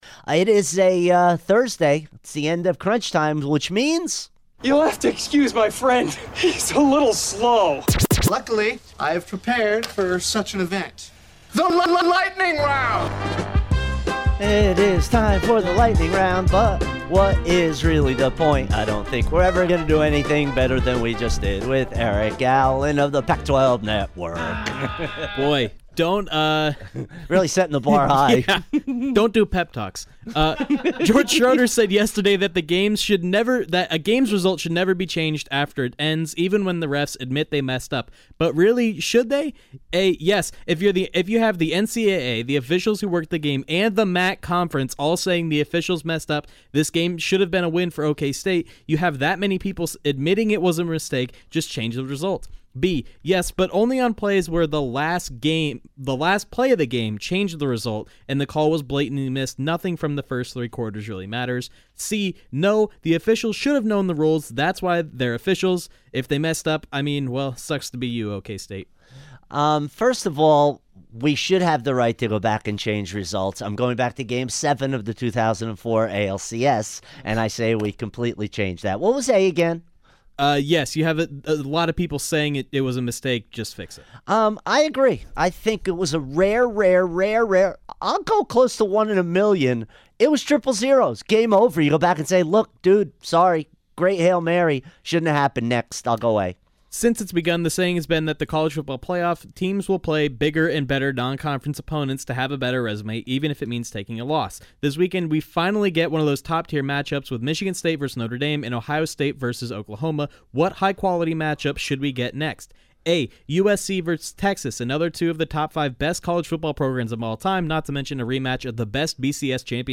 goes through the days top stories rapid-fire style